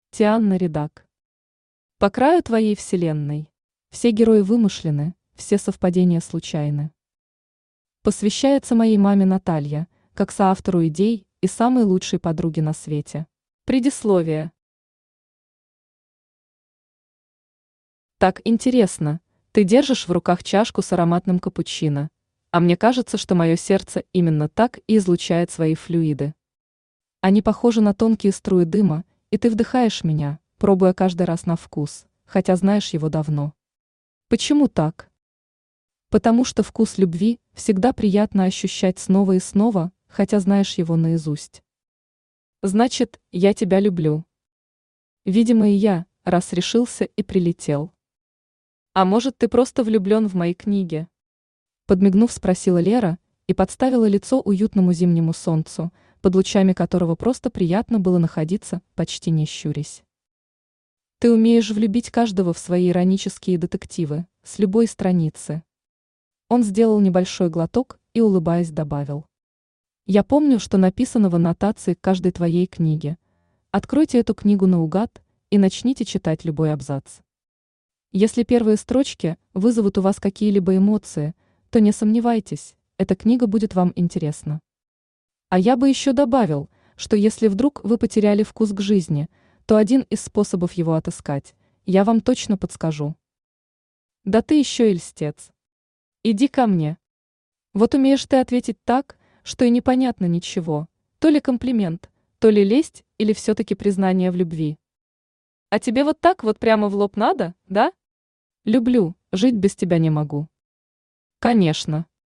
Аудиокнига По краю твоей вселенной | Библиотека аудиокниг
Aудиокнига По краю твоей вселенной Автор Тианна Ридак Читает аудиокнигу Авточтец ЛитРес.